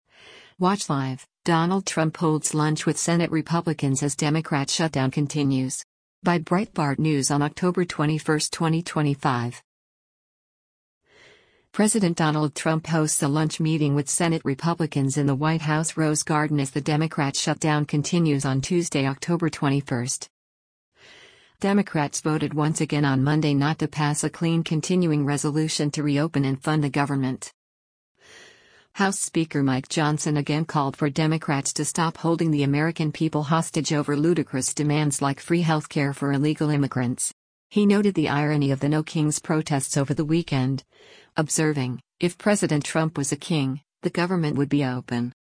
President Donald Trump hosts a lunch meeting with Senate Republicans in the White House Rose Garden as the Democrat shutdown continues on Tuesday, October 21.